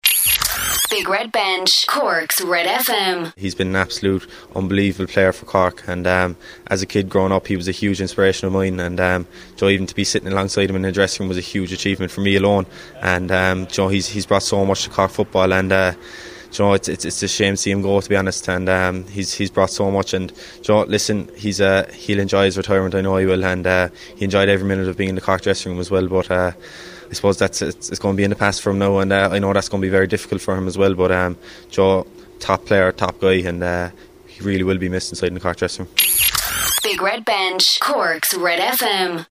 was speaking at the Marymount Hospice Entertainathon Launch,